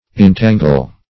intangle - definition of intangle - synonyms, pronunciation, spelling from Free Dictionary Search Result for " intangle" : The Collaborative International Dictionary of English v.0.48: Intangle \In*tan"gle\, v. t. See Entangle .